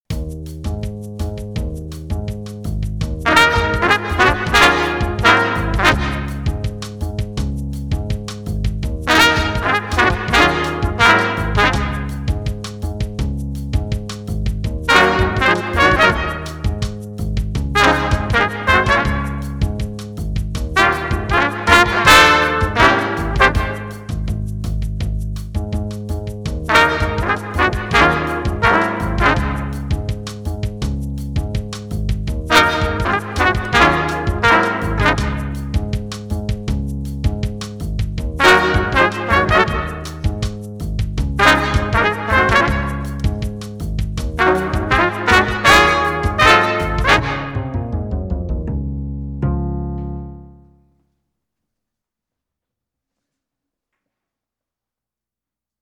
Finally we have the seasoned veteran who has performed with at least one of the top jazz schools or has been on the road for more than two years with a nationally recognized working band (this does not include any sweet bands such as Guy Lombardo, Lawrence Welk, Sammy Kay, or any local polka bands).
From our examples you should be able to recognize the subtle as well as obvious differences in articulation and emphasis applied to each.